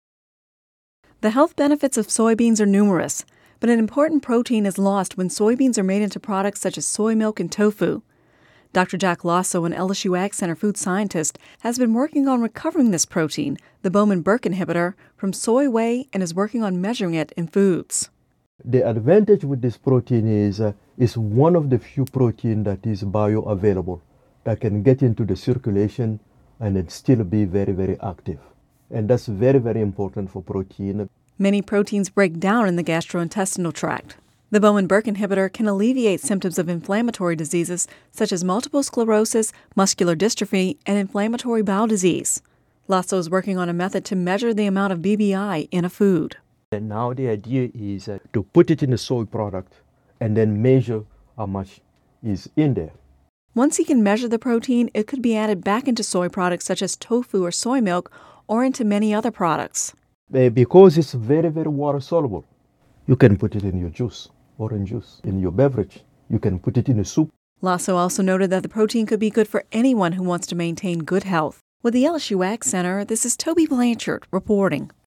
(Radio News 6/28/10) The health benefits of soybeans are numerous, but an important protein is lost when soybeans are made into products such as soy milk and tofu.